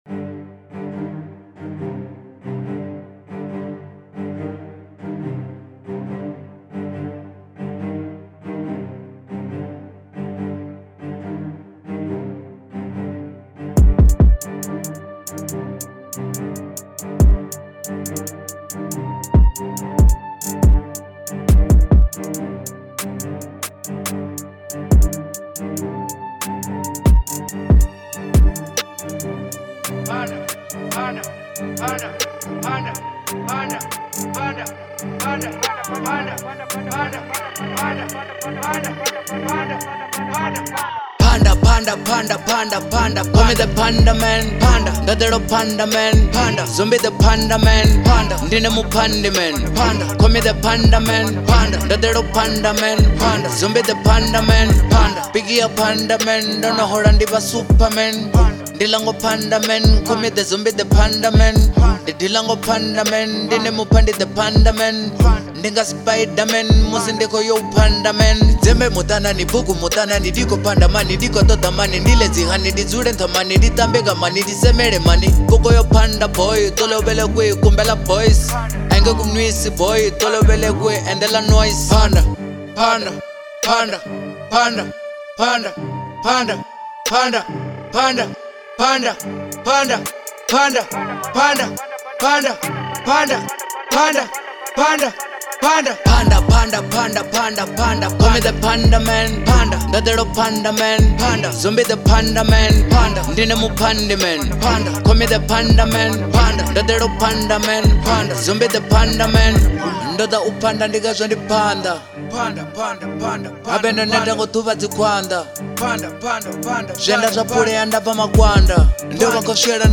03:05 Genre : Venrap Size